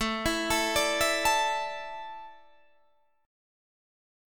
Asus4 Chord